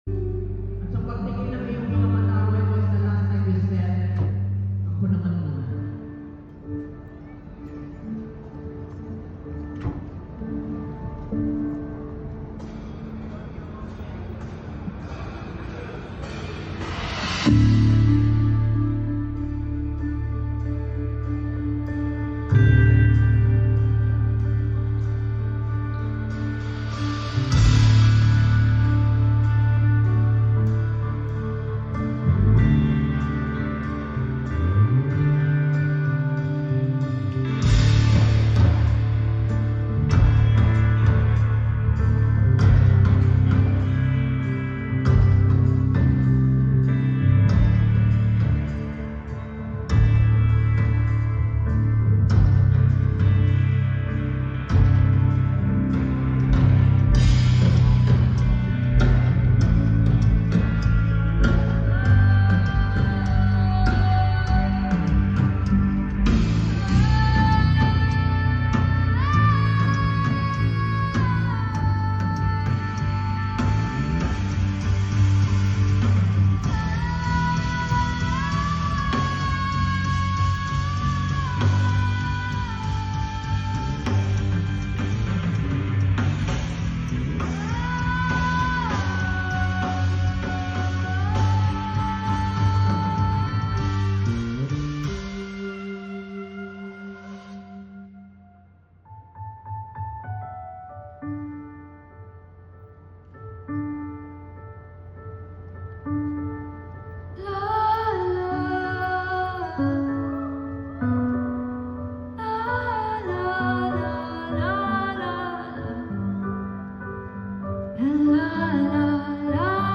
LIVE at our grand self-love conference